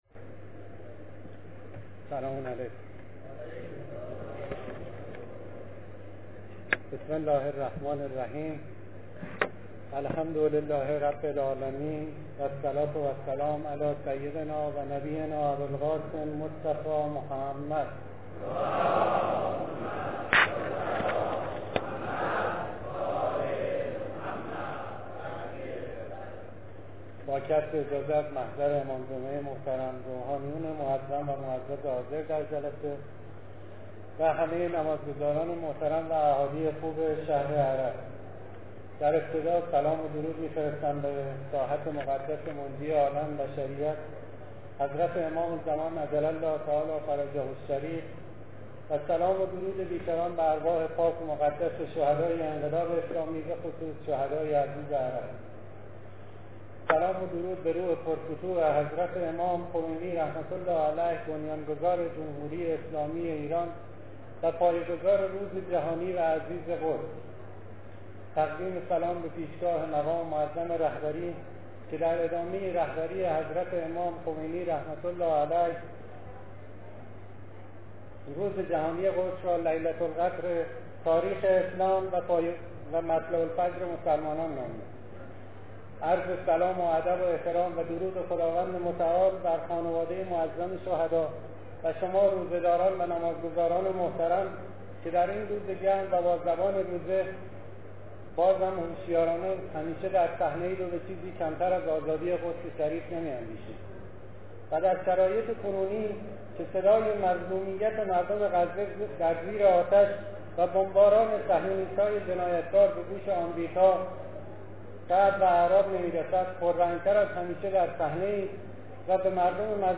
سخنرانی پیش از خطبه - آقای صالحی - بخشدار